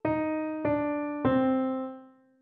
Rozpoznawanie trybu melodii (smutna,wesoła)